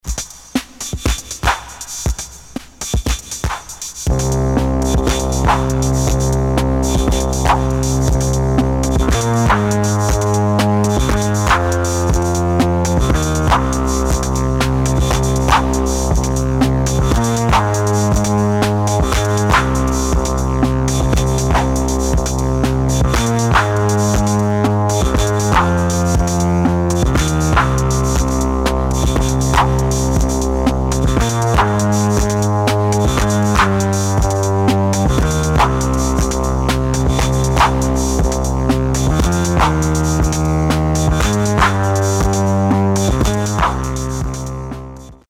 [ BASS / DOWNBAET ]